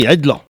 Il crie pour chasser le chien ( prononcer le cri )
Saint-Jean-de-Monts